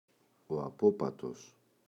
απόπατος, ο [a’popatos]